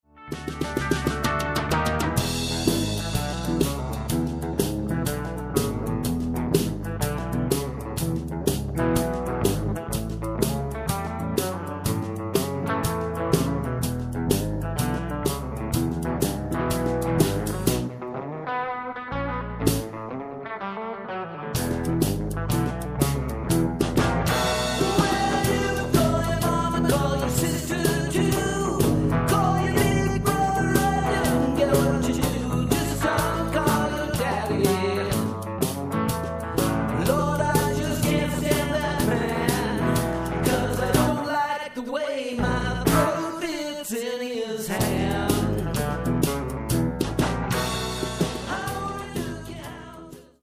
Live Band
percussion and vocals
guitars and vocals
keyboards, bass and vocals